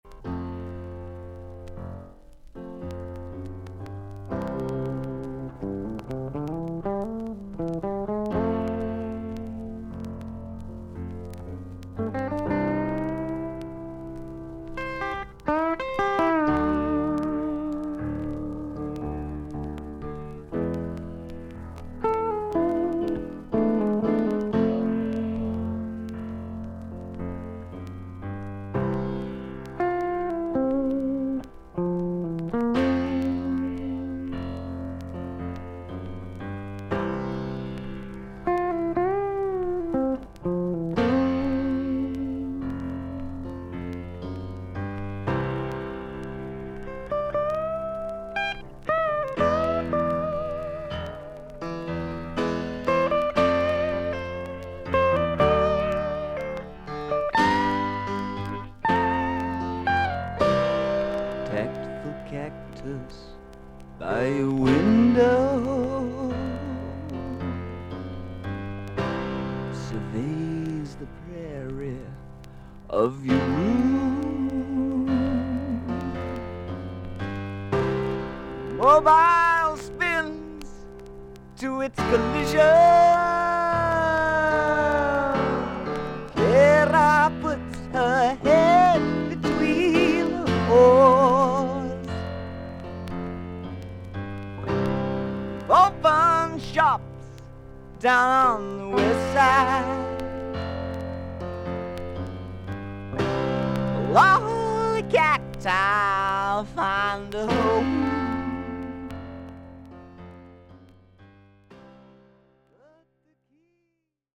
全体的に少々サーフィス・ノイズあり。少々軽いパチノイズの箇所あり。音はクリアです。